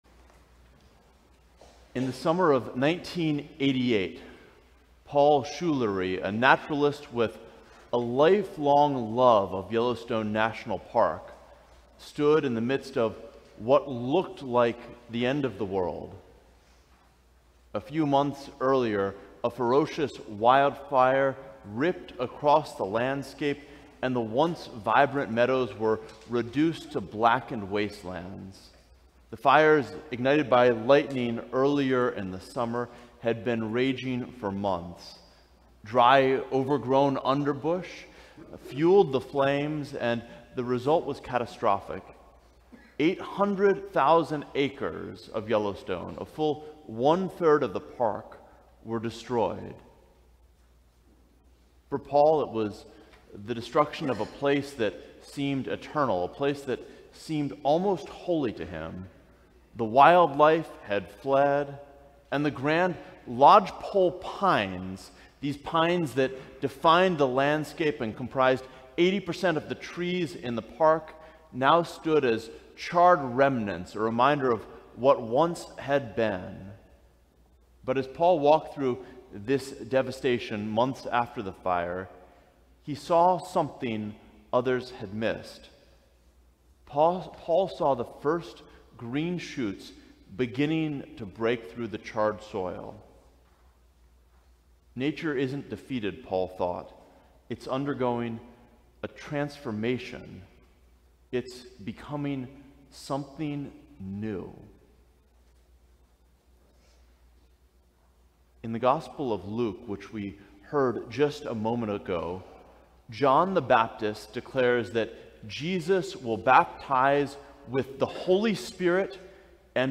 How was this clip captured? The Baptism of Our LordThe Second Sunday after the Epiphany